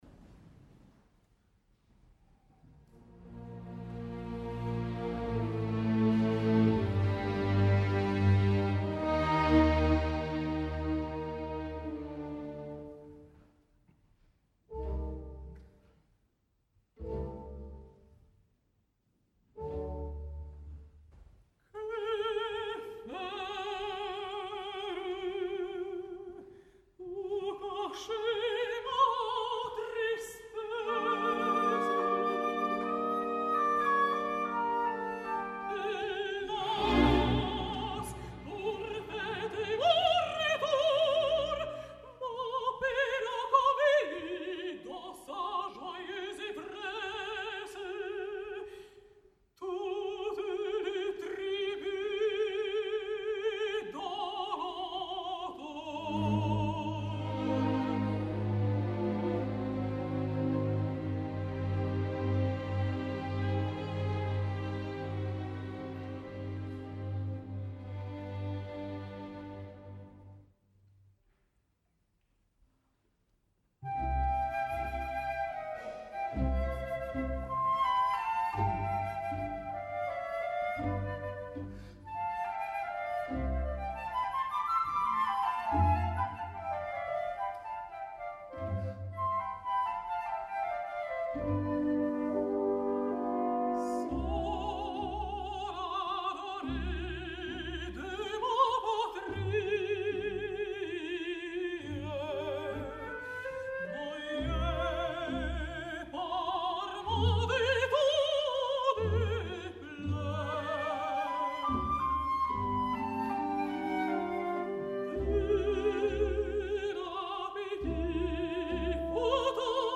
al Victoria Hall de Ginebra